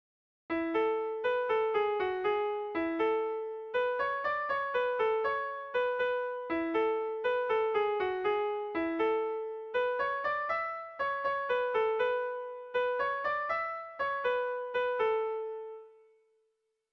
Erlijiozkoa
A1A2